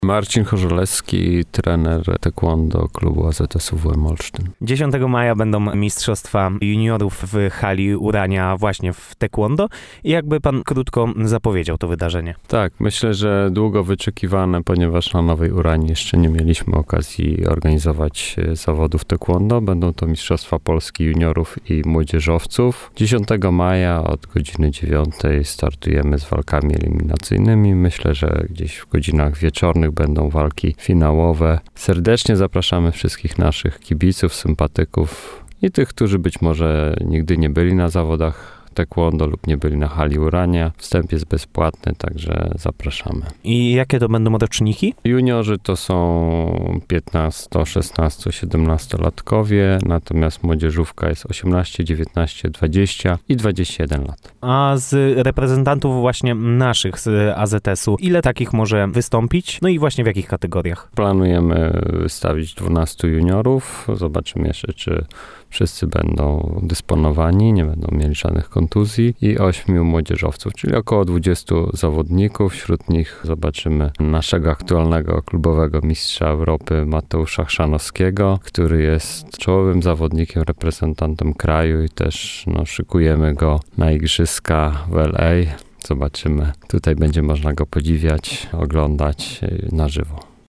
– mówił w naszym studiu